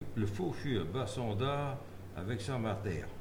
Saint-Hilaire-de-Riez
Langue Maraîchin
Catégorie Locution